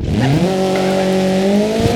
Index of /server/sound/vehicles/lwcars/renault_alpine